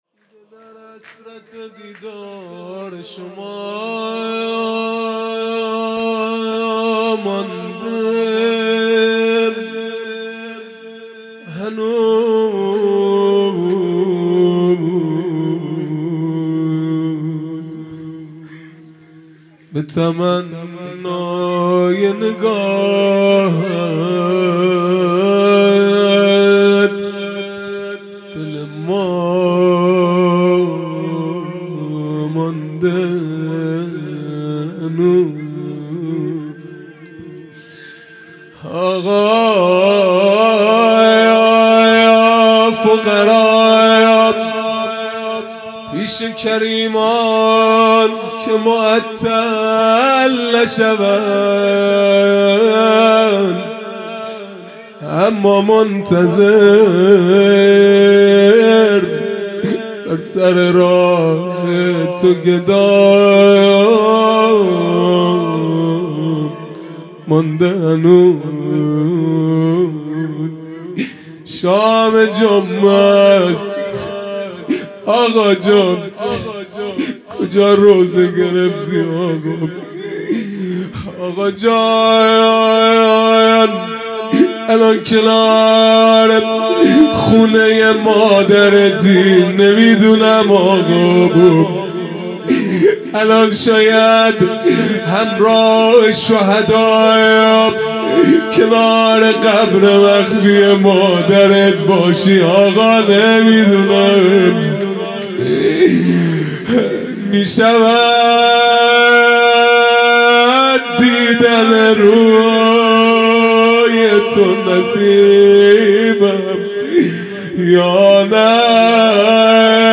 خیمه گاه - هیئت بچه های فاطمه (س) - مناجات پایانی | هنوز به تمنای نگاهت
عزاداری فاطمیه اول | شب اول